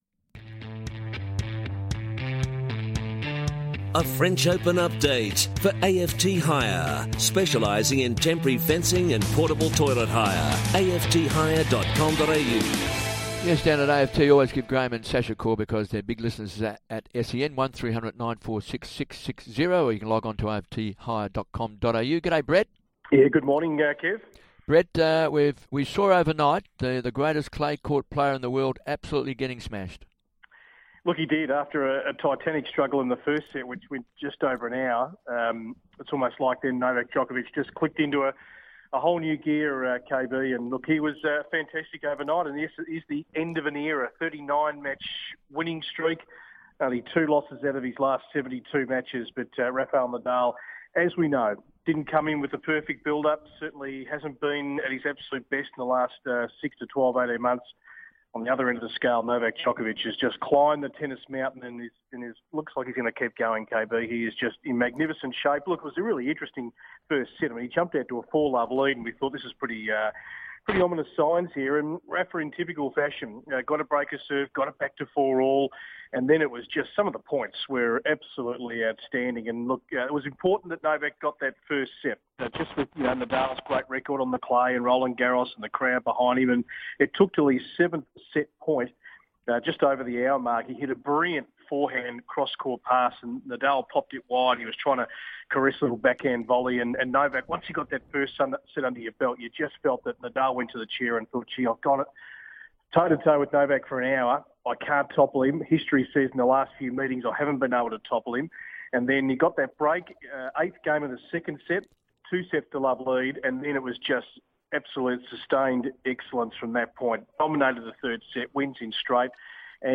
calls in to chat about the French Open on Hungry For Sport